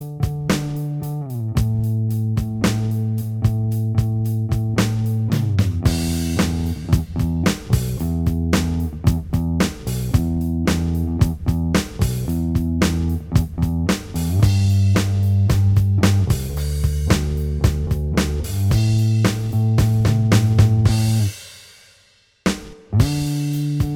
Minus Guitars Soft Rock 3:08 Buy £1.50